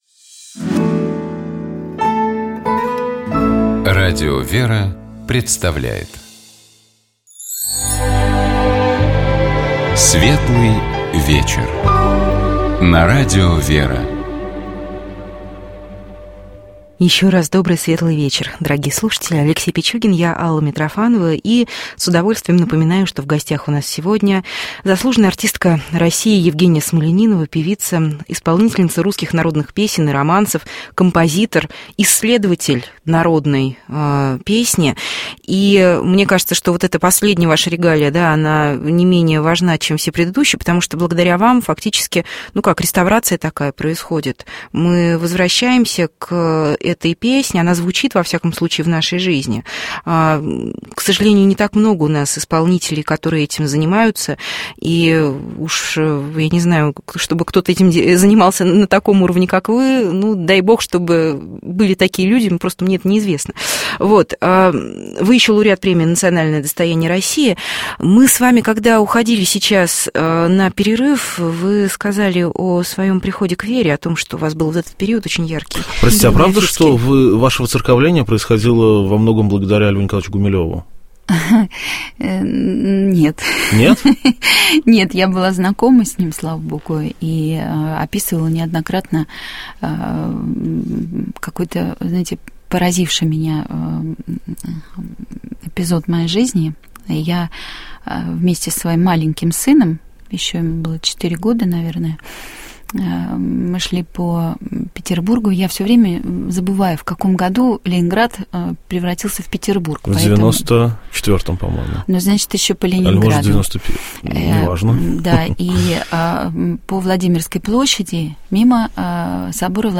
У нас в гостях была заслуженная артистка России, певица Евгения Смольянинова.